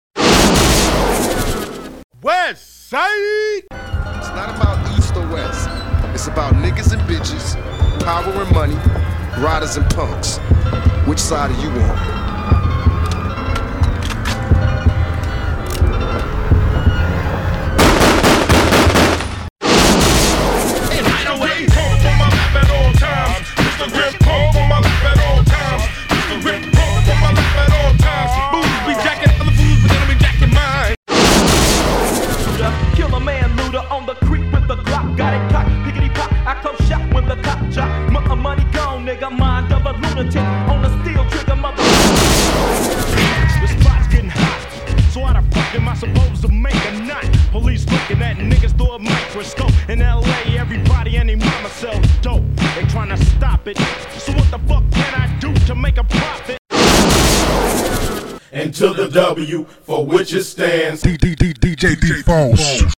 Classic Westcoast mix music from the heavyweights.